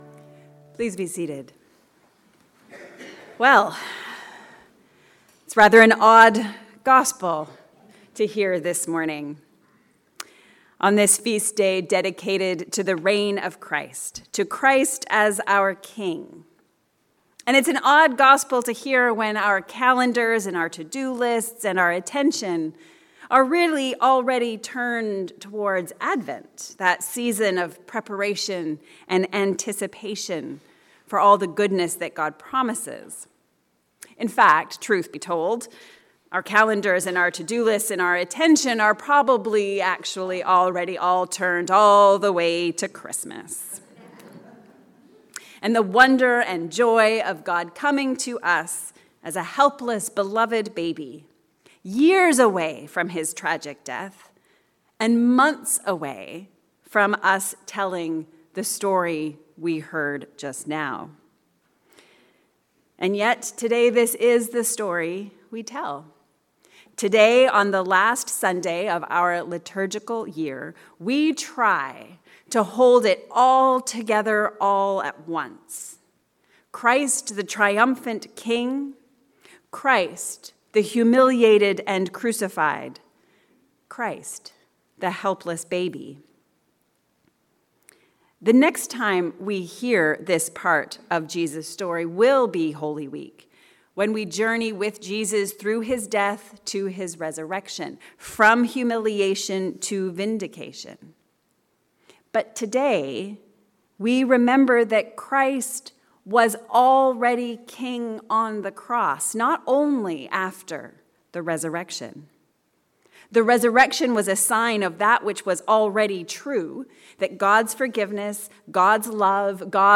Whose subjects are we? A Sermon for the Feast of the Reign of Christ